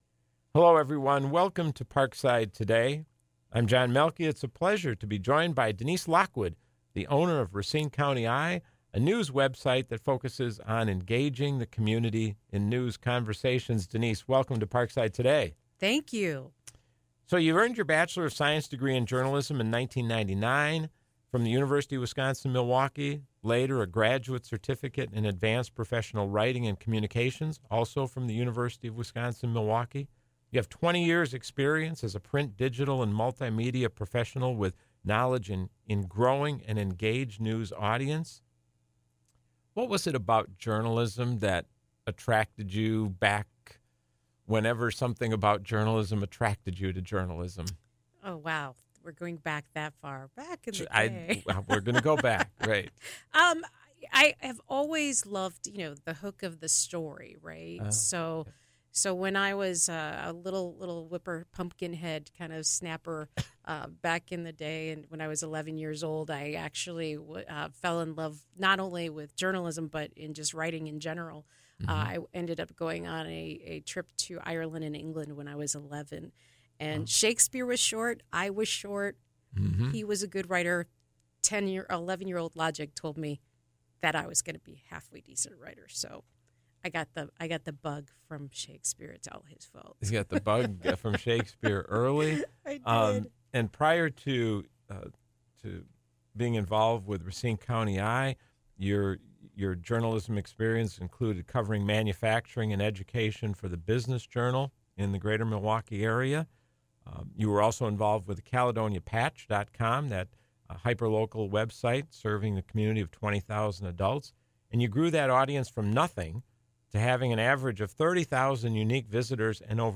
This interview originally aired on WIPZ 101.5 FM on Tuesday, April 9, at 4 p.m.